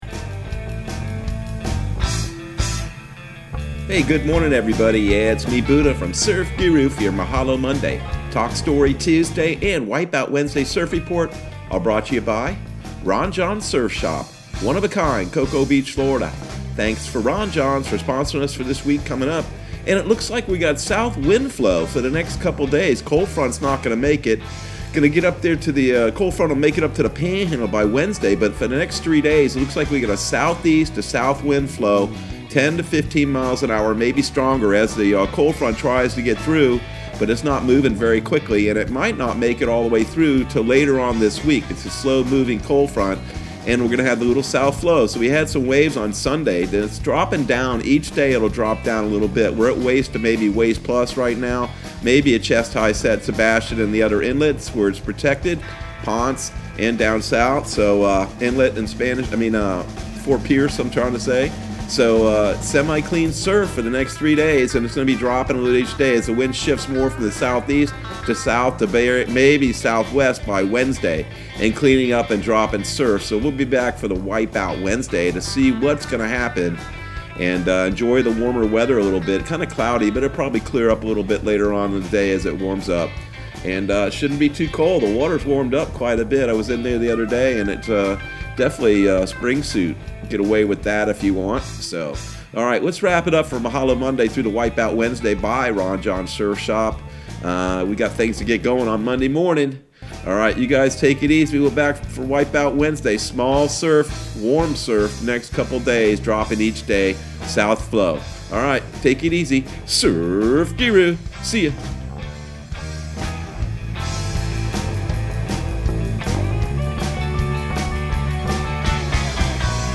Surf Guru Surf Report and Forecast 01/25/2021 Audio surf report and surf forecast on January 25 for Central Florida and the Southeast.